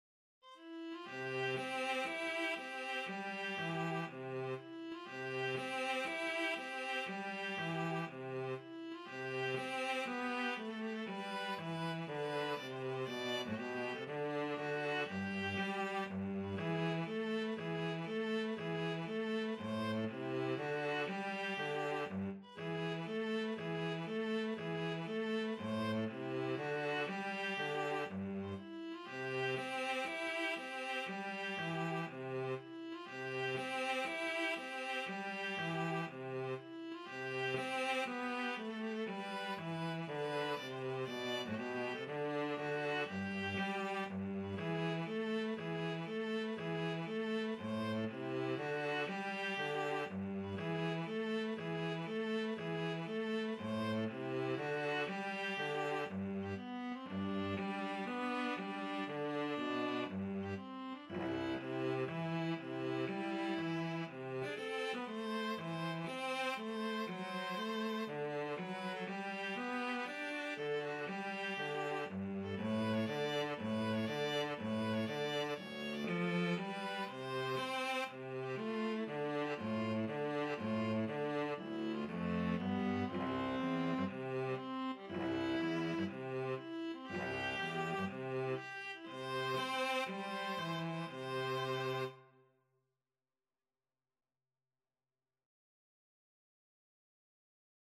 Maestoso =120
4/4 (View more 4/4 Music)
Classical (View more Classical Viola-Cello Duet Music)